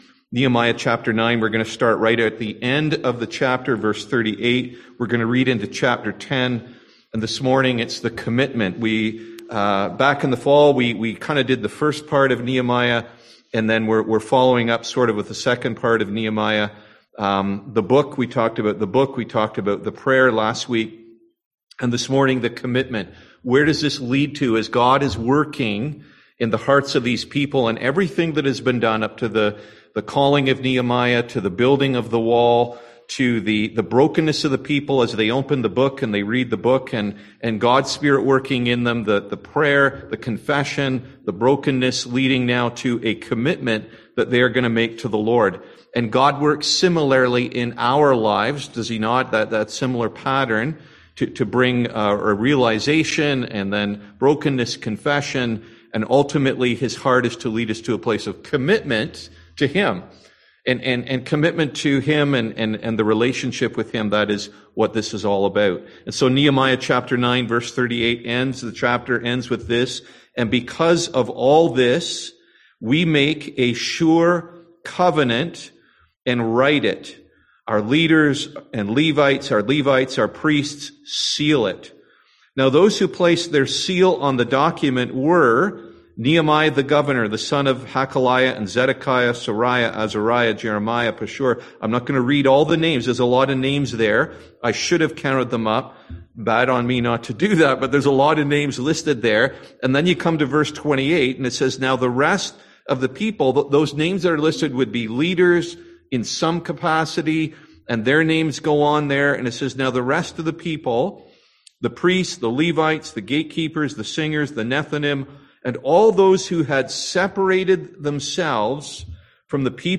Passage: Nehemiah 9 Service Type: Sunday AM